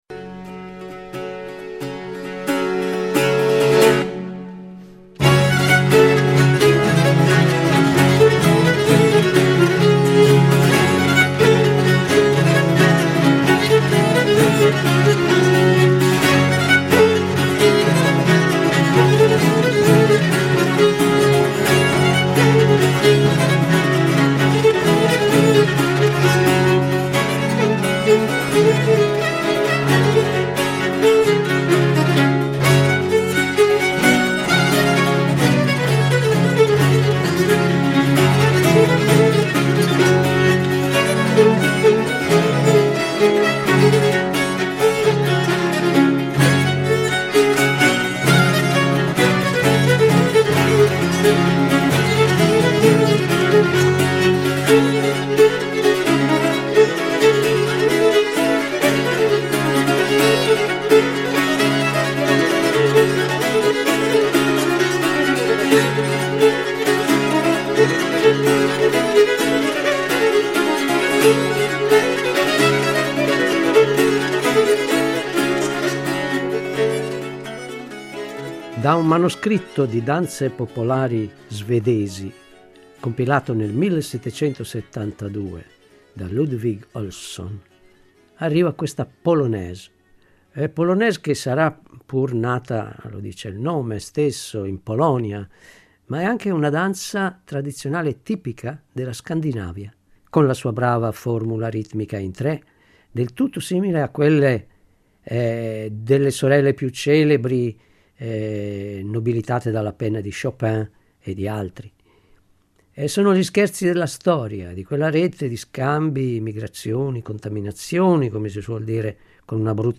La Recensione